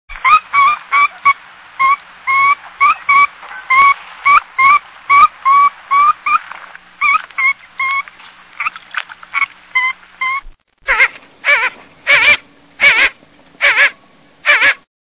Blue-winged Teal
Blue_winged_Teal.mp3